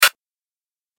دانلود آهنگ کلیک 48 از افکت صوتی اشیاء
دانلود صدای کلیک 48 از ساعد نیوز با لینک مستقیم و کیفیت بالا
جلوه های صوتی